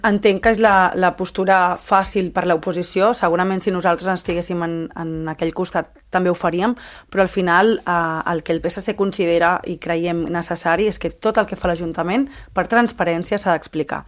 A tot això, la portaveu socialista ha defensat la política comunicativa del govern de les crítiques de l’oposició per una despesa excessiva, just quan s’acaba de crear la plaça de tècnic de l’àrea per reconversió d’una existent.